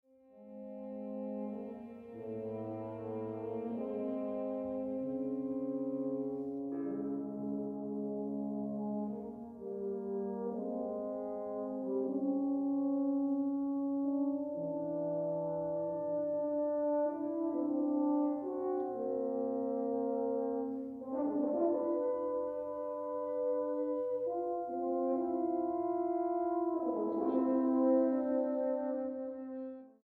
ópera infantil